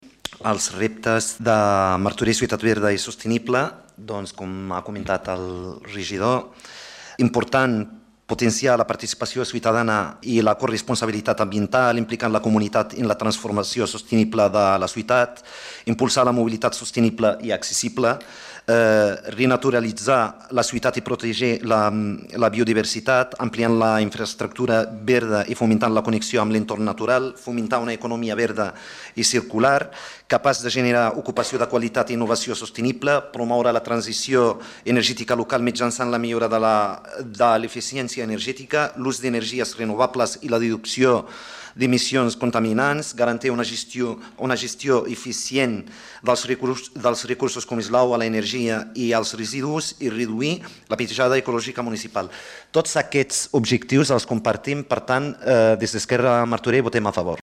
Soulimane Messaoudi, regidor d'ERC